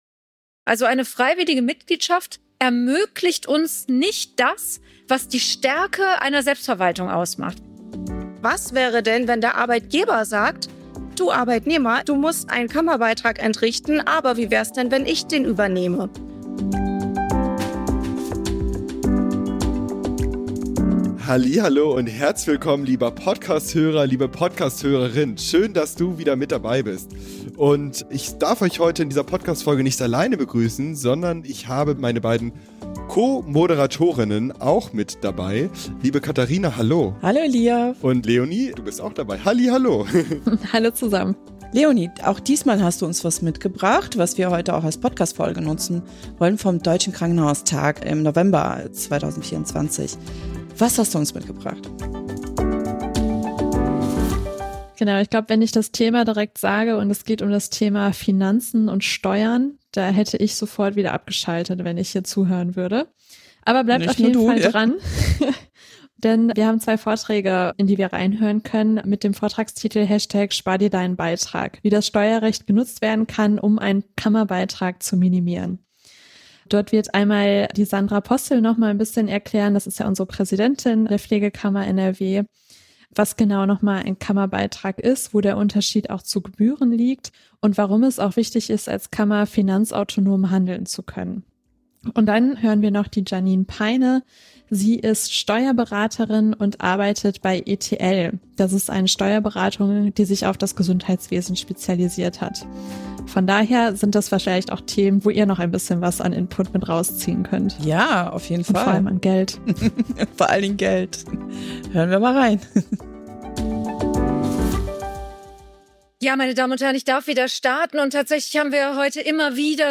In dieser Sonderfolge vom Deutschen Krankenhaustag 2024 sprechen wir darüber, was Pflegefachpersonen wirklich zahlen müssen, welche Entlastungen möglich sind und warum finanzielle Unabhängigkeit für die Pflegekammer NRW so ein wichtiges Thema ist. Die Pflegekammer NRW soll die Stimme der Pflege stärken – doch was bedeutet das finanziell für die Mitglieder?